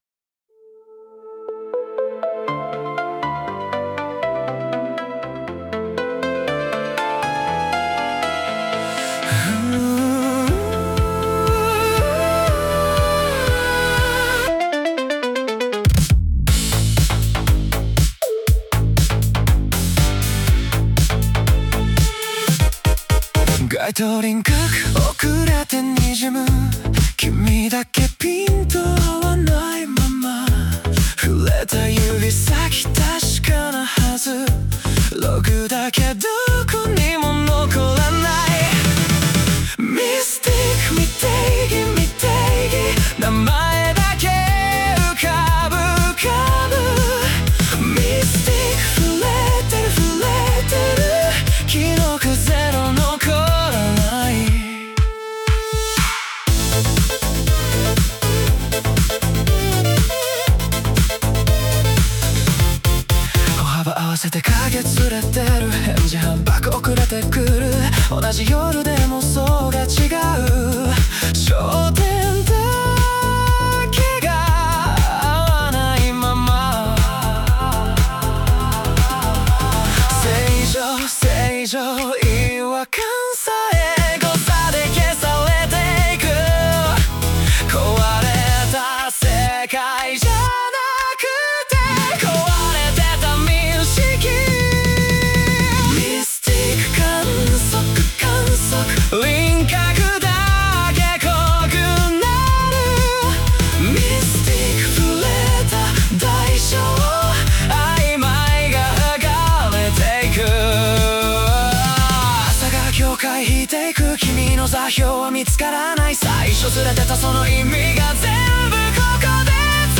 歌ものフリー素材 bgm音楽 無料ダウンロード 商用・非商用ともに登録不要で安心してご利用いただけます。
男性ボーカル